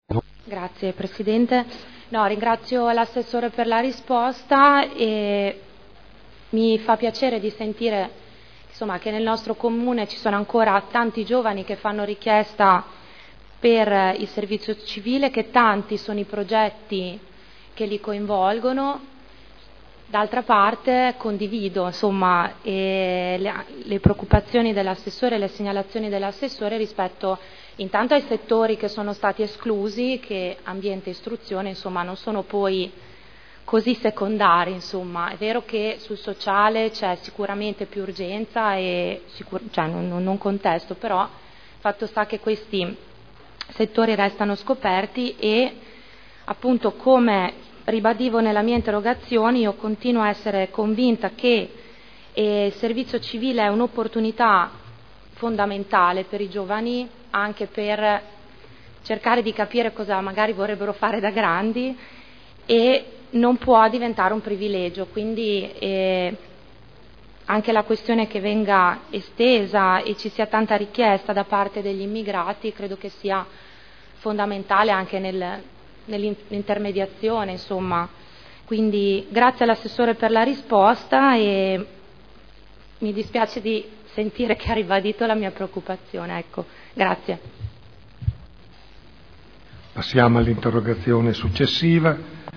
Elisa Sala — Sito Audio Consiglio Comunale